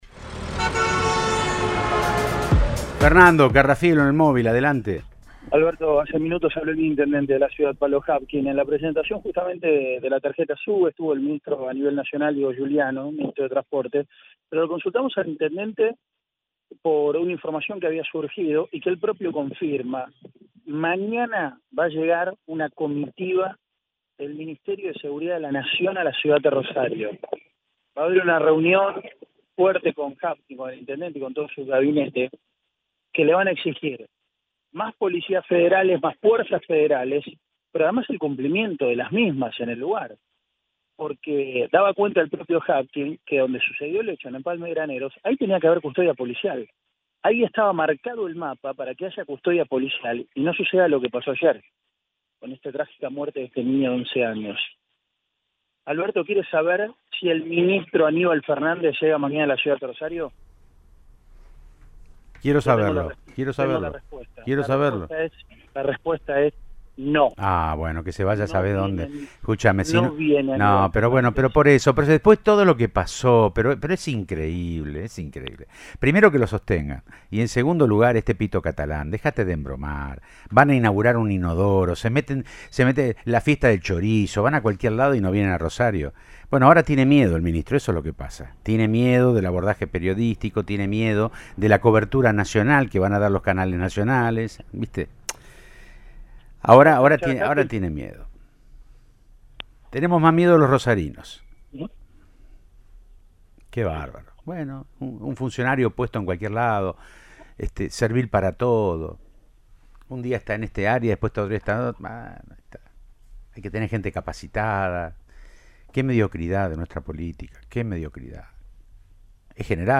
El intendente Pablo Javkin analizó el momento que vive la ciudad tras el asesinato del niño de 11 años y la balacera en la escuela de zona sur.
“A las 20.30 se produce este hecho en un lugar que debía estar custodiado por gendarmería, si que se produzca ninguna persecución”, comentó al móvil de Cadena 3 Rosario, en Siempre Juntos, sobre la balacera.